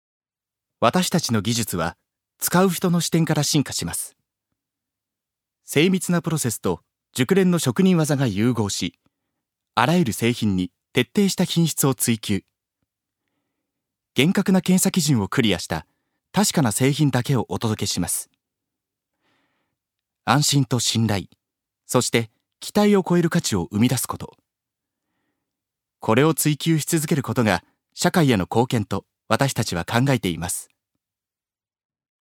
所属：男性タレント
ナレーション１